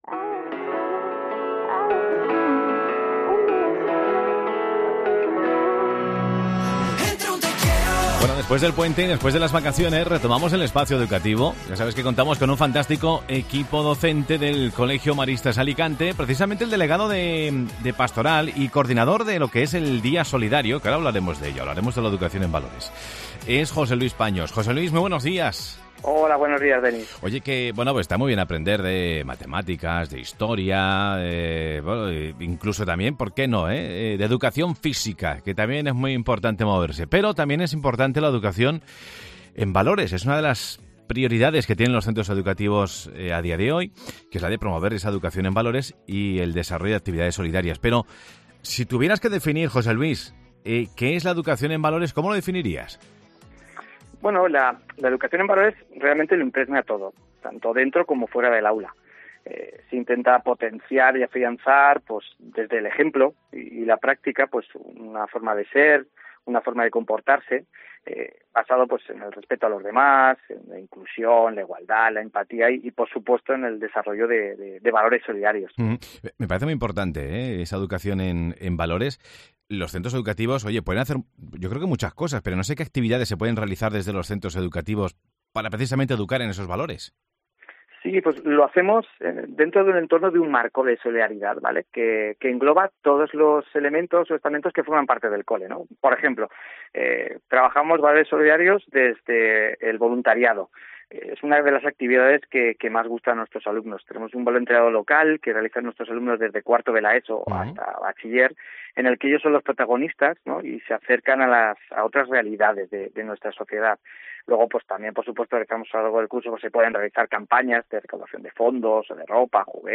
El evento abierto a todo el público, conmemora el 25 aniversario con actividades dirigidas a todos los públicos. Escucha la entrevista